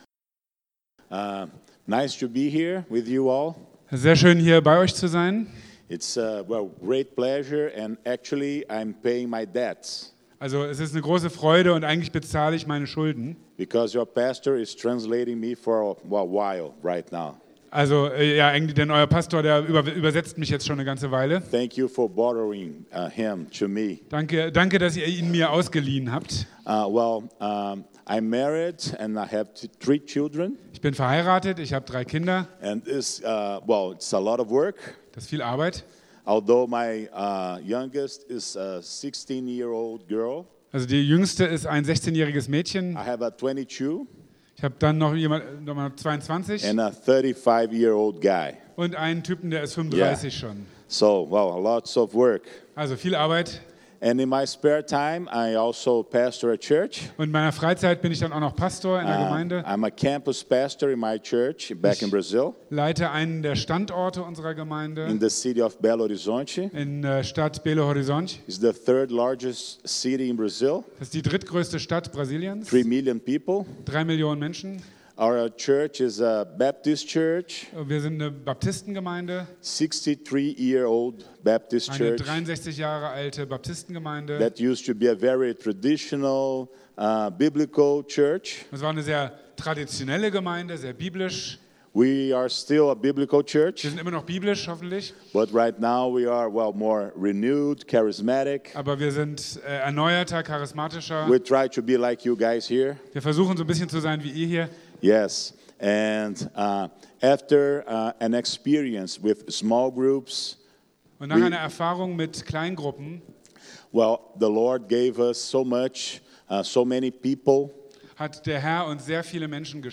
(Englisch mit Übersetzung)